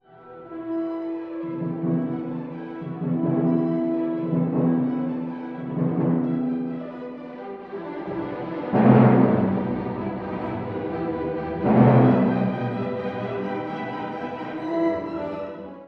↑古い録音のため聴きづらいかもしれません！（以下同様）
生き生きとしたリズムと、舞踏的なモチーフが特徴的。
力強いビートや民族舞踊的な旋律が随所に感じられます。
中間部（トリオ）はやや落ち着いた雰囲気を見せます。